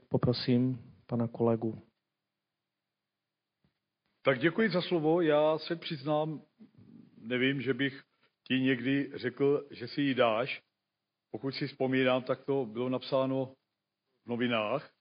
Dozvuky přeběhlictví na 15. ZM 2024
Zastupitel Kryštof prohlásil, že je (Kastner) přeběhlík a že se s tím musí naučit žít.